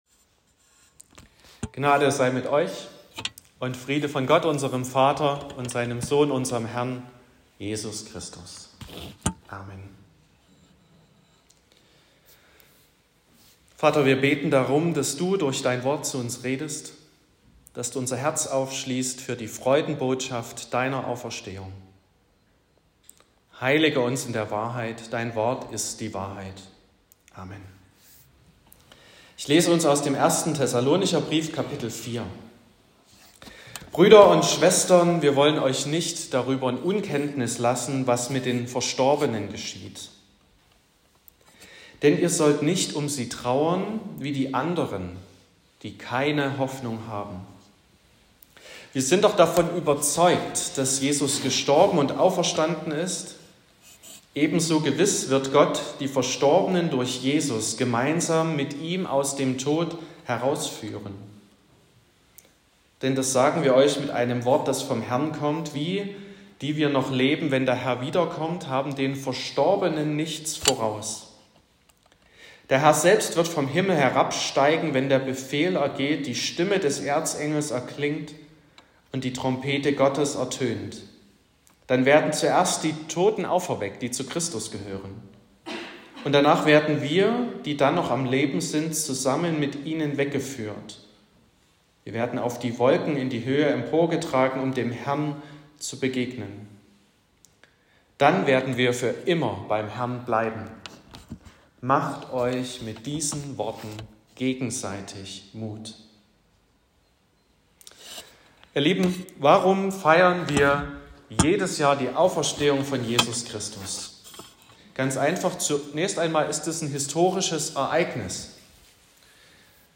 Osternachtsfeier
OrtKirche Schönbrunn
Predigt und Aufzeichnungen